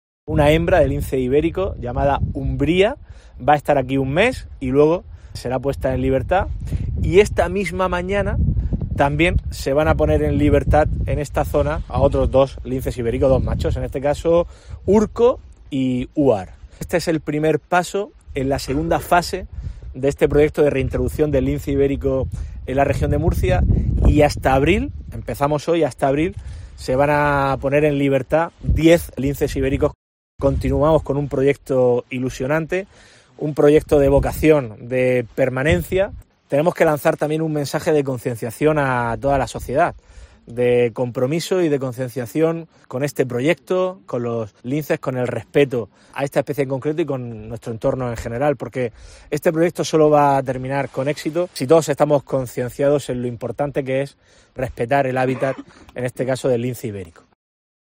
Fernando López Miras, presidente CARM